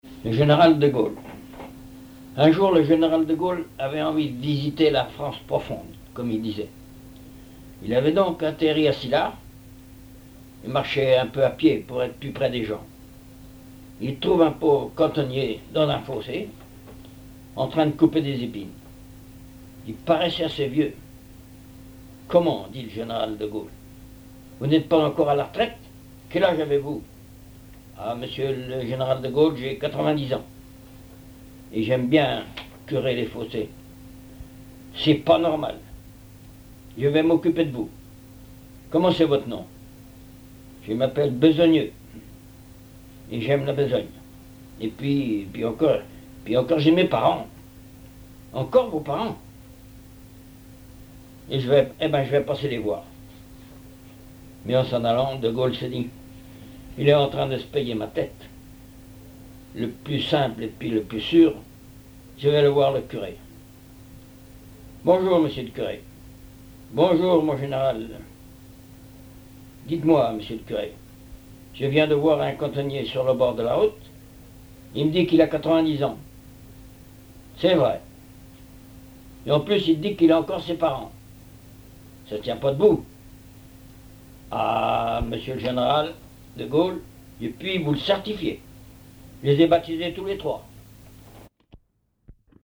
Genre sketch
contes, récits et chansons populaires
Catégorie Récit